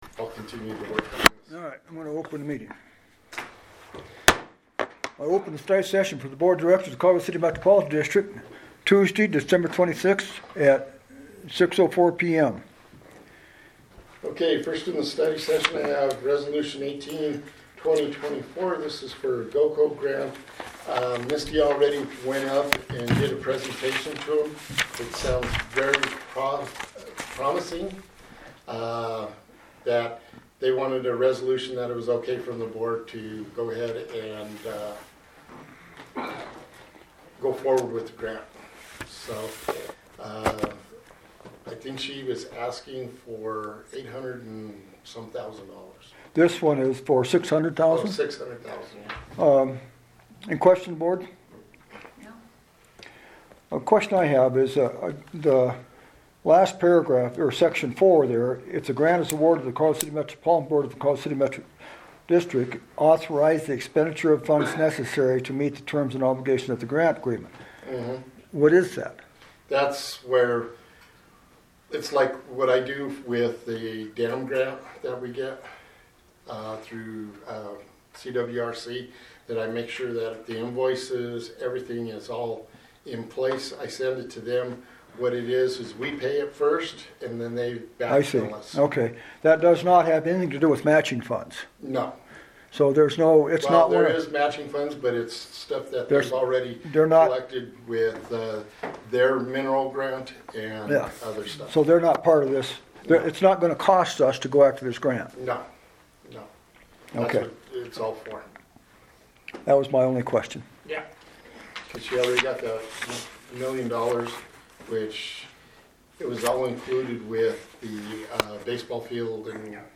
Board Meeting